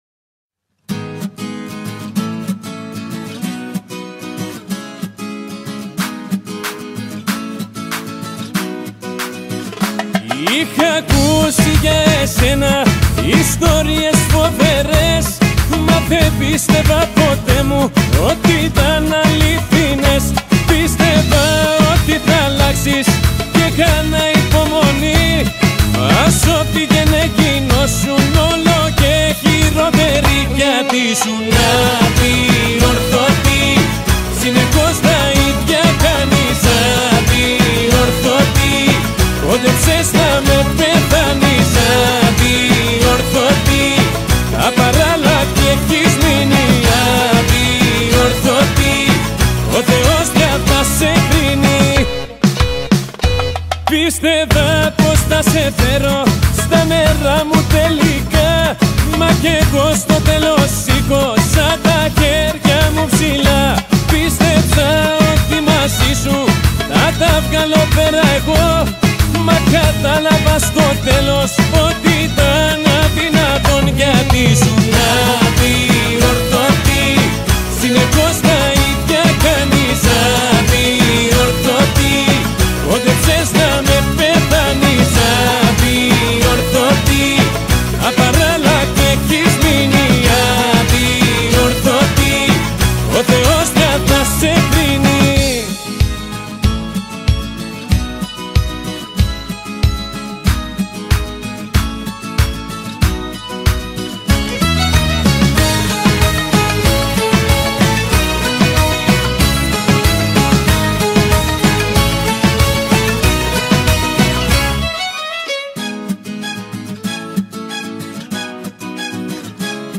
یجورایی سبک اهنگا ترکیو داره.